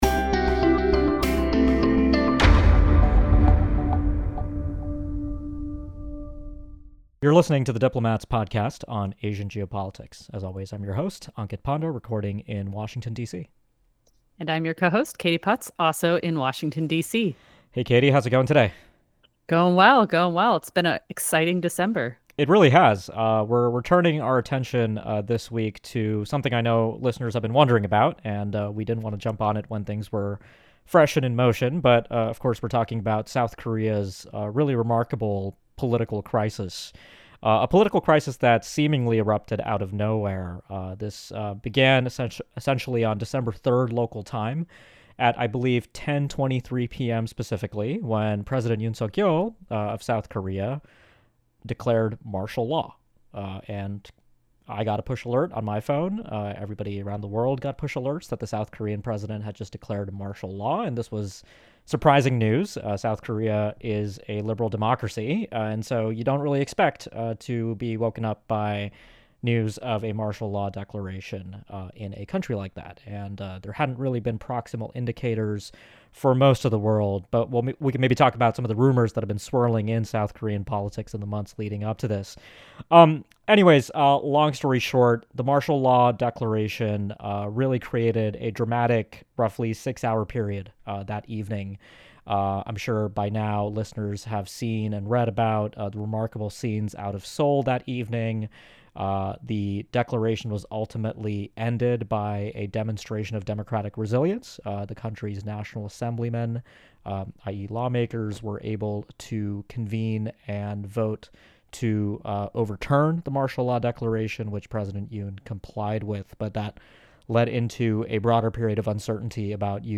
The Diplomat ’s Asia Geopolitics podcast hosts